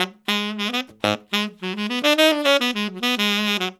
Index of /90_sSampleCDs/Zero-G - Phantom Horns/SAX SOLO 2